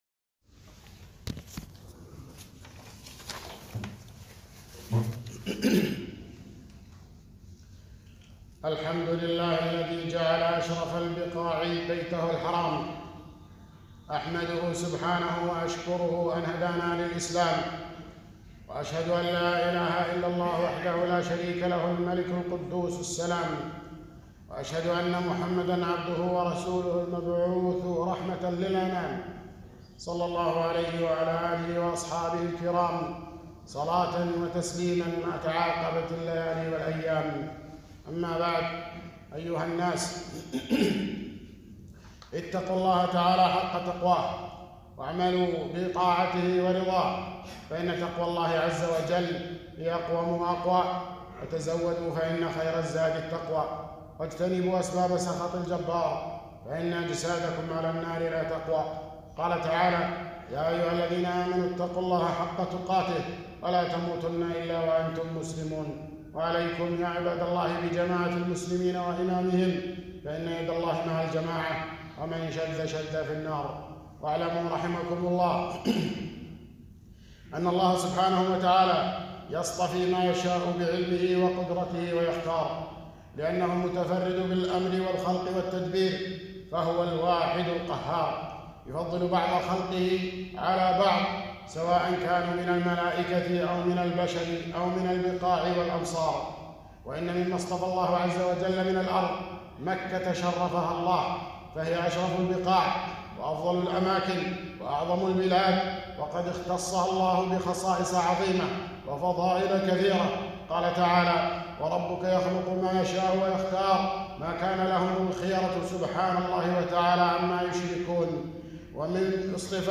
خطبة - فضائل مكة وحرمة الاعتداء عليها